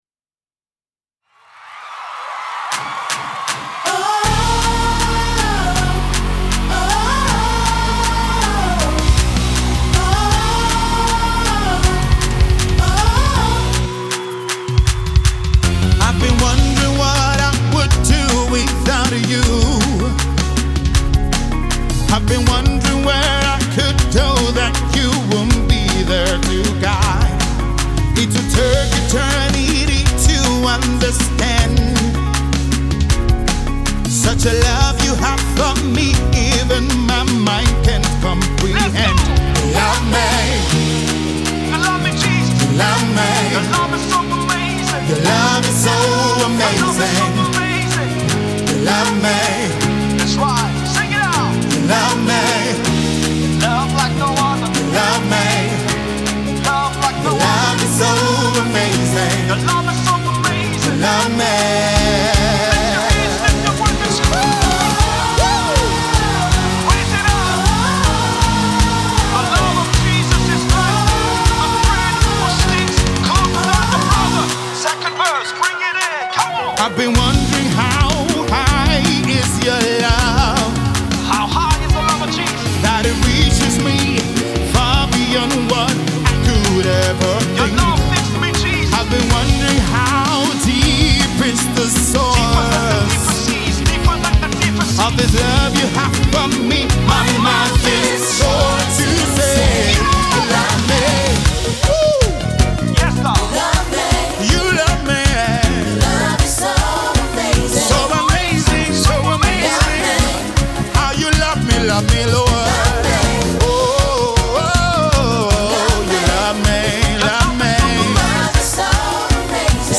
is a Love song